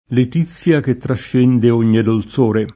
dolciore [ dol ©1 re ] s. m.